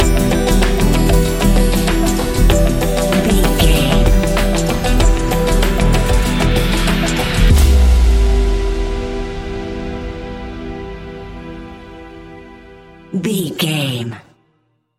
Ionian/Major
E♭
electronic
techno
trance
synths
synthwave